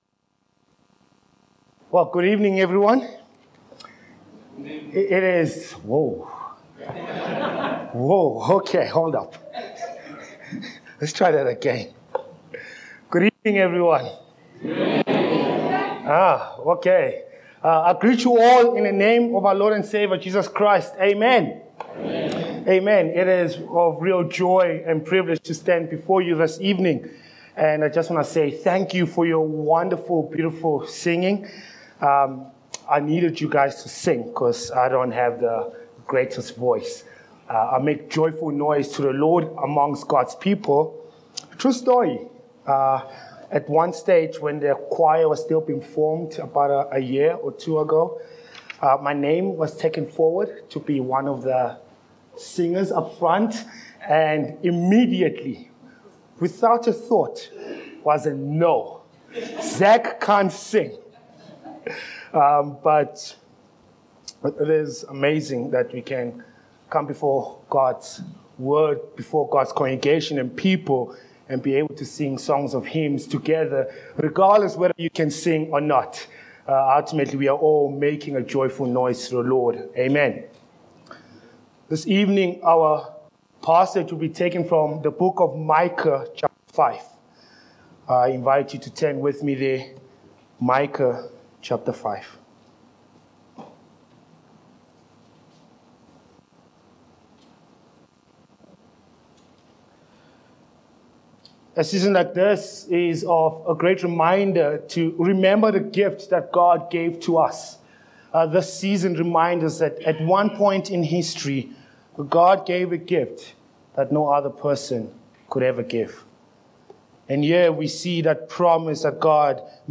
Micah 5:1-5 Service Type: Evening Passage